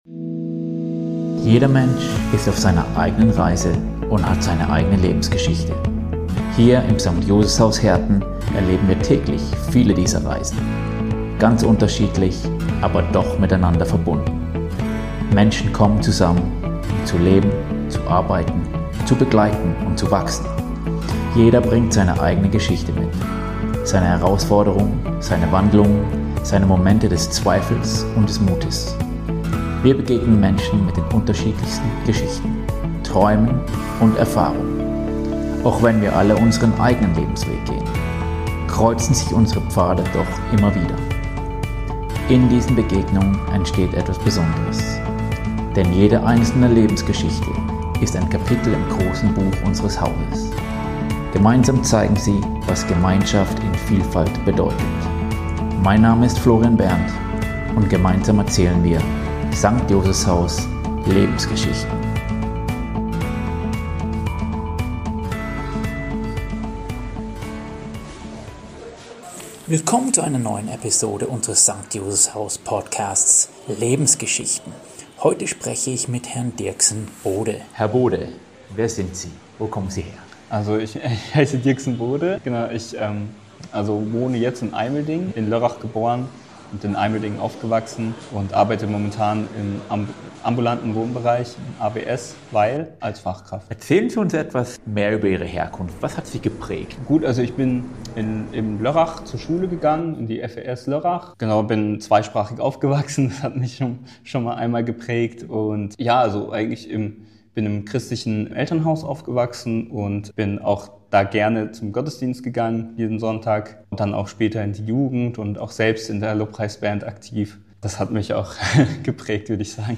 Aufgewachsen in einer christlichen Familie spricht er über seinen persönlichen Glauben, verschiedene Berufswege und die Suche nach Zugehörigkeit und Berufung. Ein ehrliches, inspirierendes Gespräch über Herkunft, Entscheidungen und den Mut, den eigenen Weg zu finden.